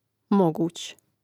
mȍgūć moguć prid.